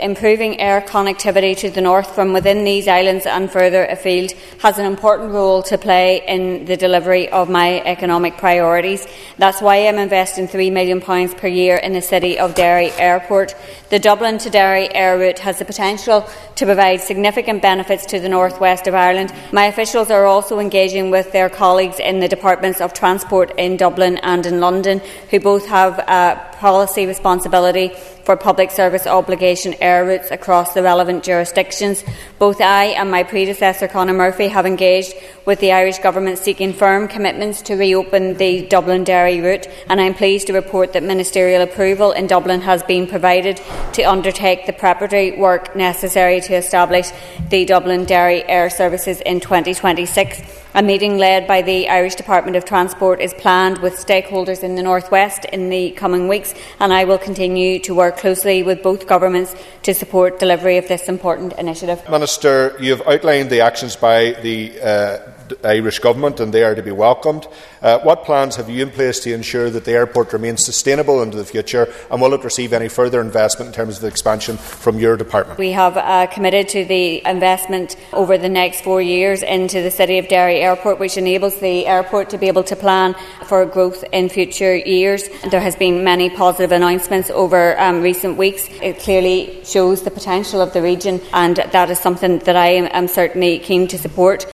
The North’s Economy Minister has told the Assembly her department will continue to fund City of Derry Airport as a driver for development across the entire North West region.
Answering questions from West Tyrone MLA Daniel McCrossan, Minister Caoimhe Archibald said the prospect of a new Derry Dublin air service is very welcome, but stressed that decisions on CPOs can only be made in Dublin and London.